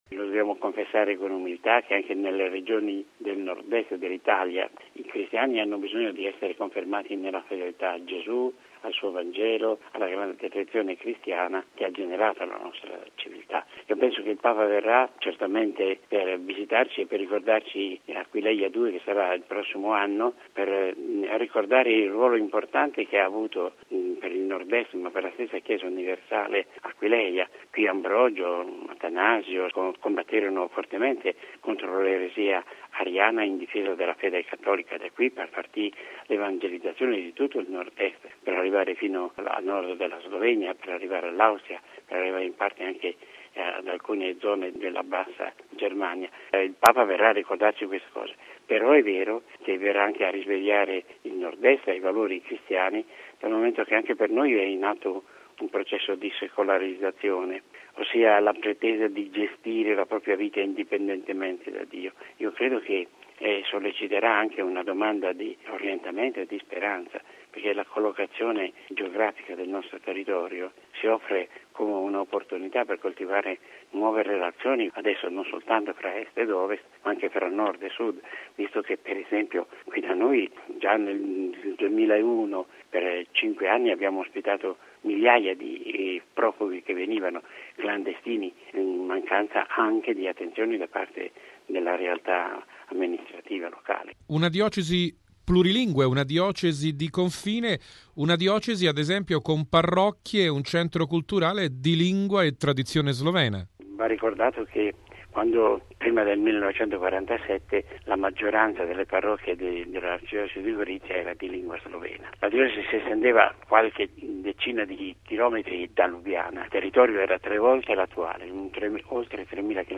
Sulla visita del Papa nel Nordest d’Italia e in particolare ad Aquileia, ascoltiamo l’arcivescovo di Gorizia Dino De Antoni.